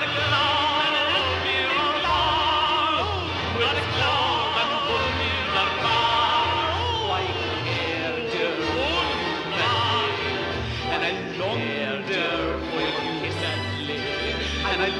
Yes i like to clean whole CD tracks that came with loudnesswar, so we have files up to 6 minutes.
Testclip, piece of 1952er song clip fixed: